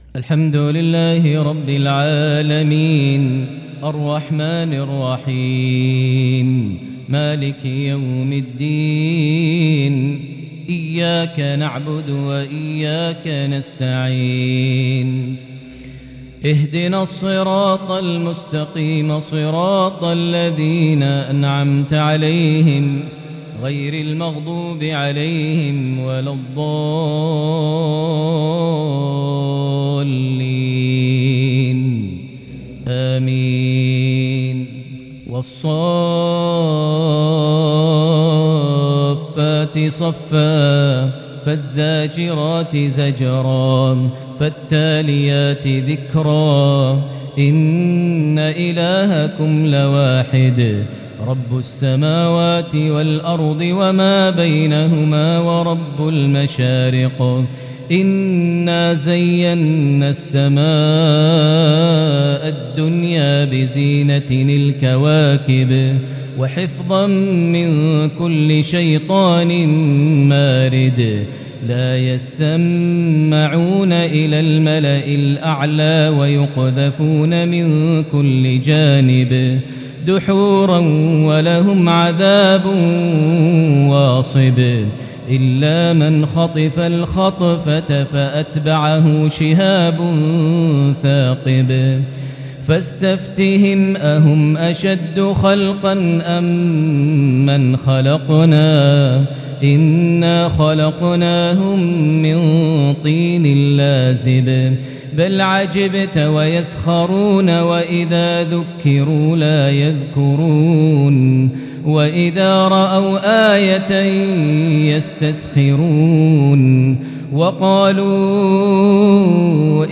lsha 1-2-2022 prayer from Surah As-Saaffat 1-49 > 1443 H > Prayers - Maher Almuaiqly Recitations